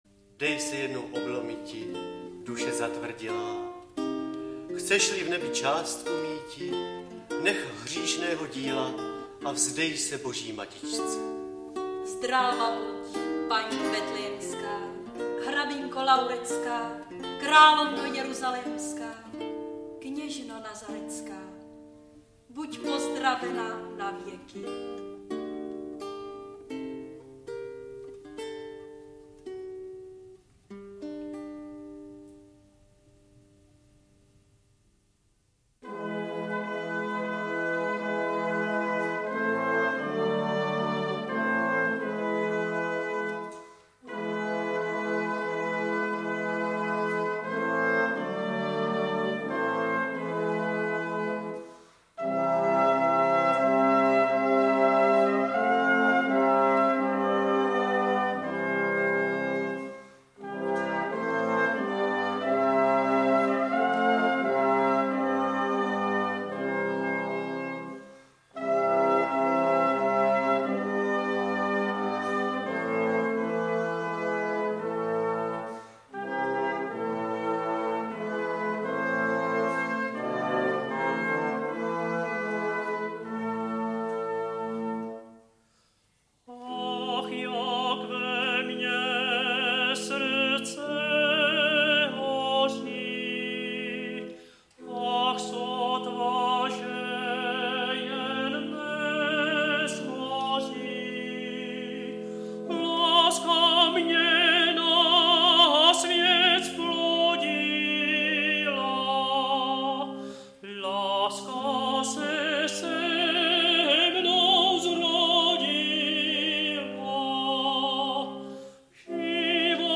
Poezie
natočeno ve zvukovém studiu Karpofon (AudioStory)